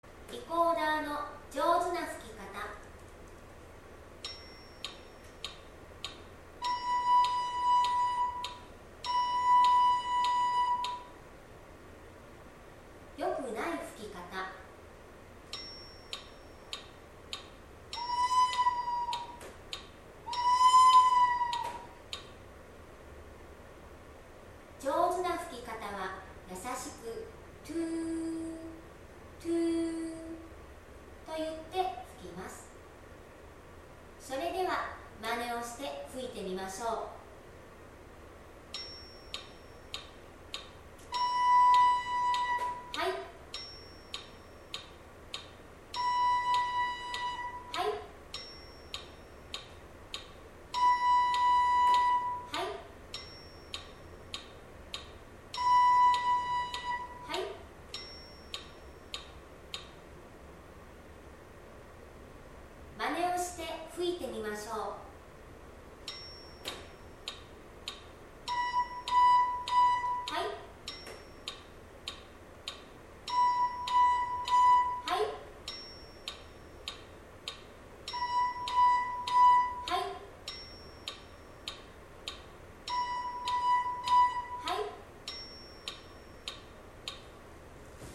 3年、音楽リコーダー
5月23,24日の音楽のかだいです。リコーダー「シ」の音の練習をしてみましょう。